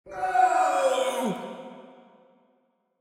GuardScream1.ogg